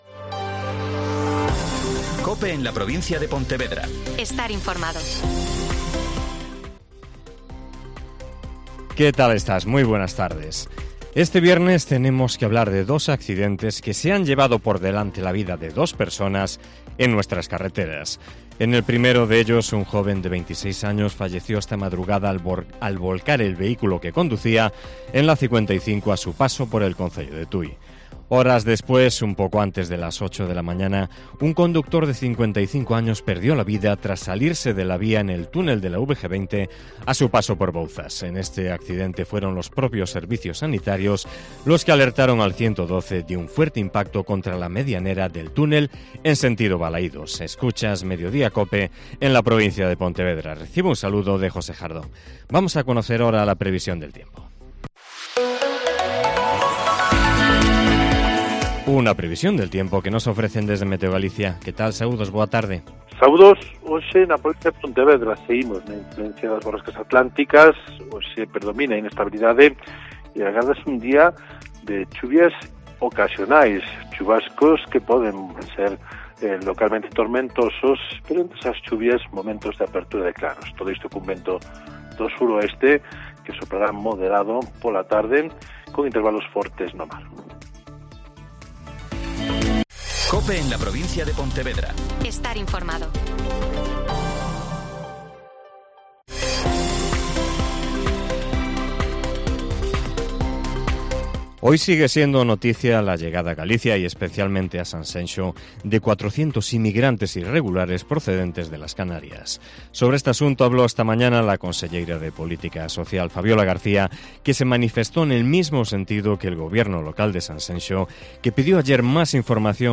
Mediodía COPE en la provincia de Pontevedra - Informativo